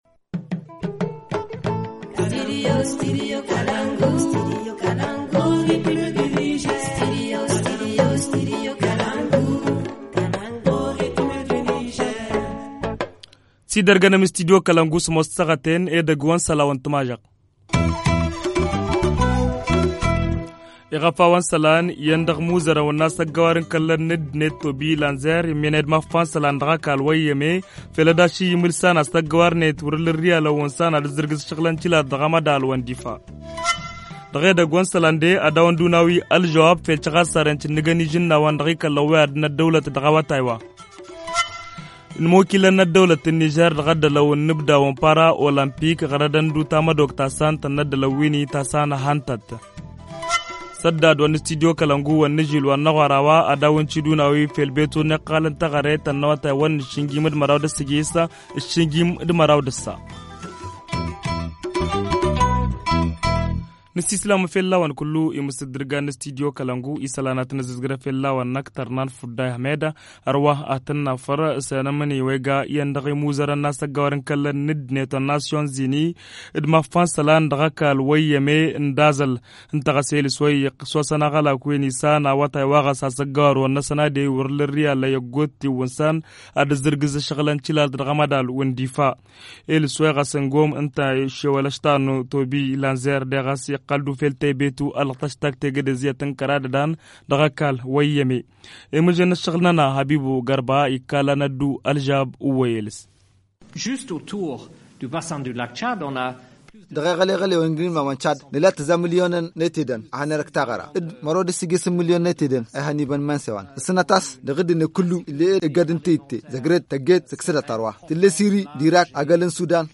2. Montée des eaux du fleuve Niger : Des cas d’inondations enregistrées depuis quelques temps. A suivre le point sur la situation et les cris de cœur des sinistrés dans ce journal.